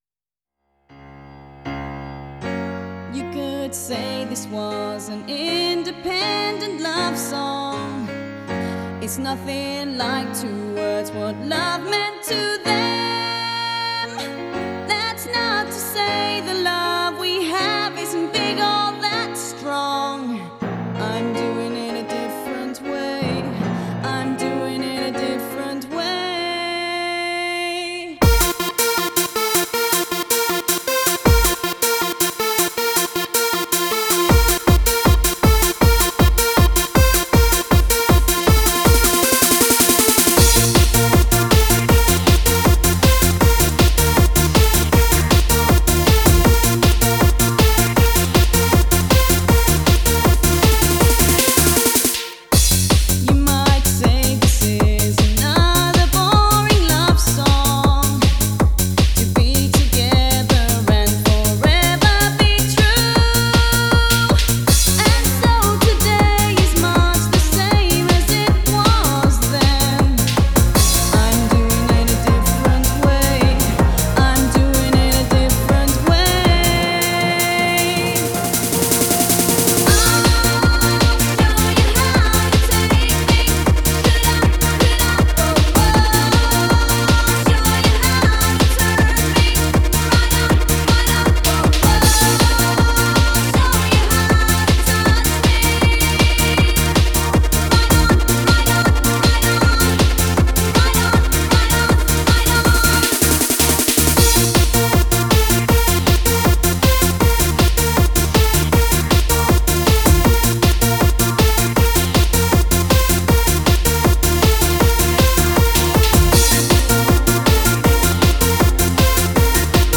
Genre: Makina.